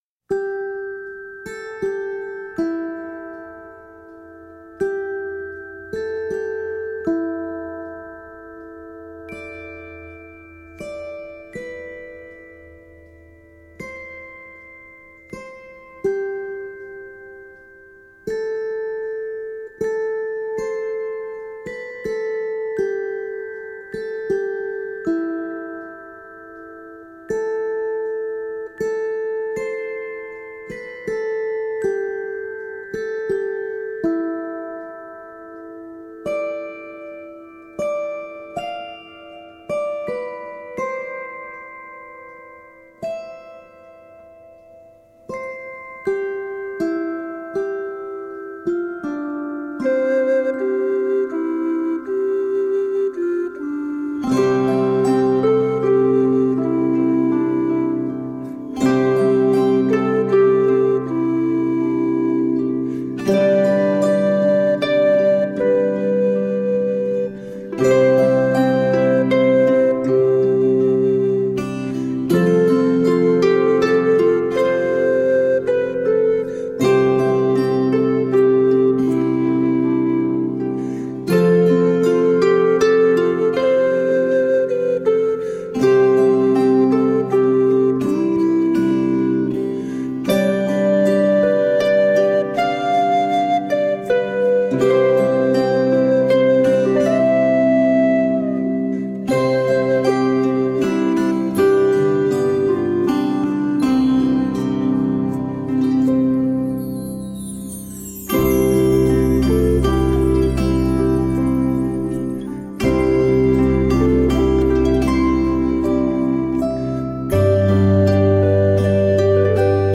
Tagged as: World, Folk, New Age, Christmas, Flute, Harp